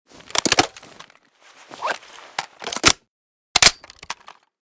m14_reload_empty.ogg.bak